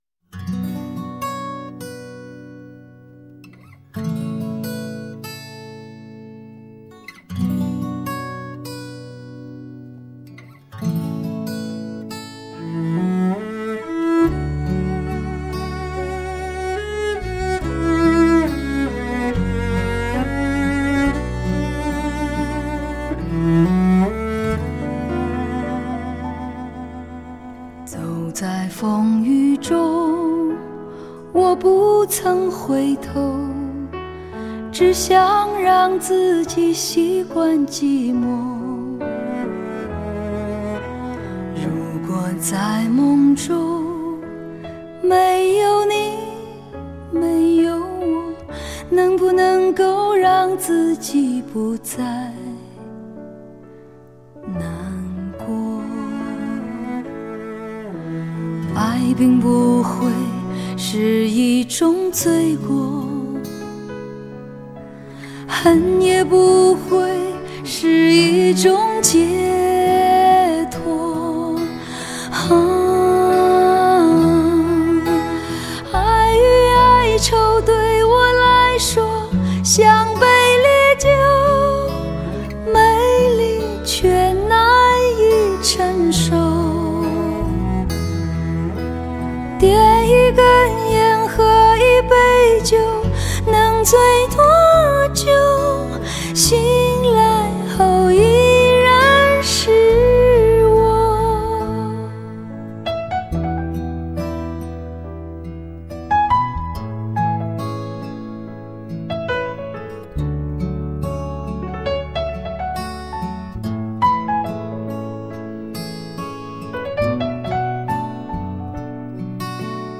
顶级音响器材试音专辑
吉他通透细腻，小提琴纤细柔和，施坦威钢琴音色迷人，磁性而富有张力的女声，沧桑而感性的演绎。